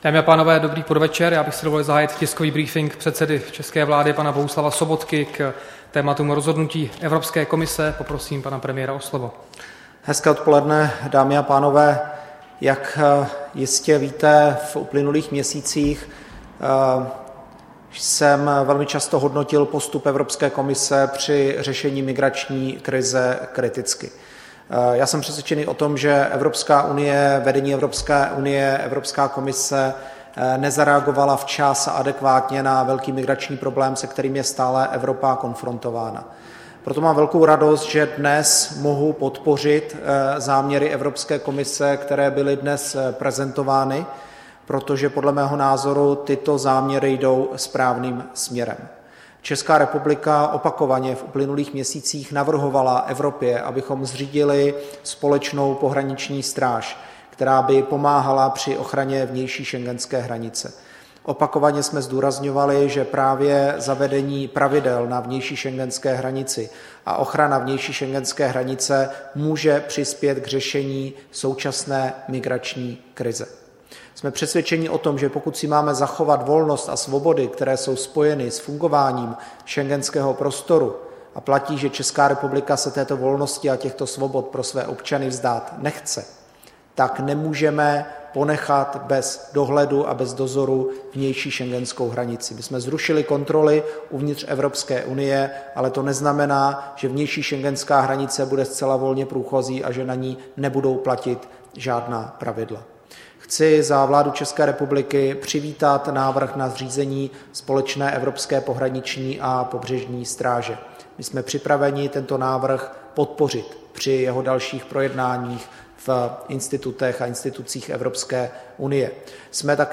Tisková konference k rozhodnutí Evropské komise zřídit evropskou pohraniční a pobřežní stráž, 15. prosince 2015